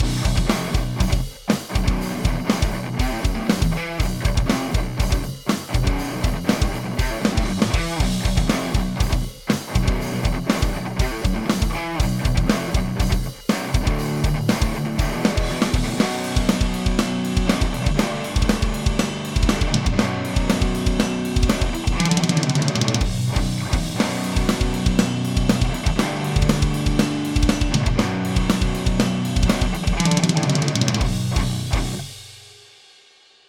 e uno senza TS attivo,
per far sentire la differenza e come le due parti devono lavorare assieme.
Gtrs_NoTS_VH4.mp3